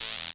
synTTE55018shortsyn-A.wav